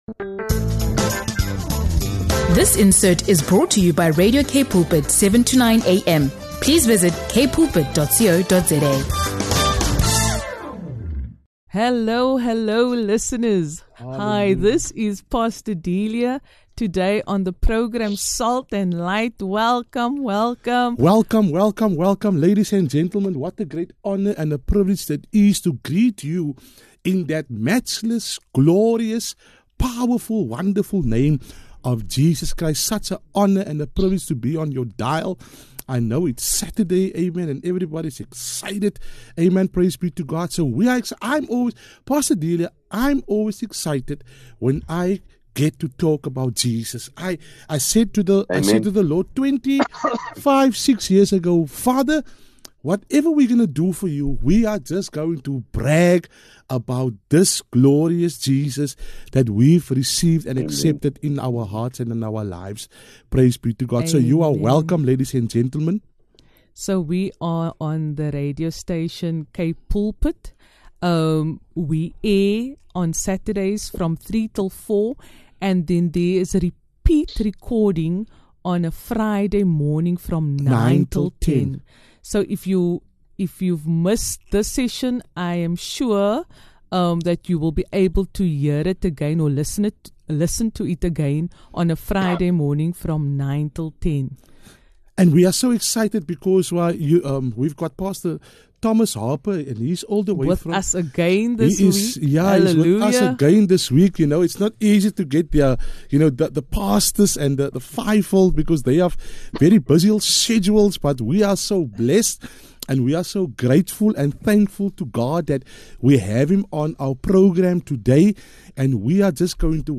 Broadcasting on Kpoopid Radio, the two dive deep into the topic of church hurt—how the enemy uses it to pull us out of position, and how God uses it to strengthen and realign us with our divine purpose.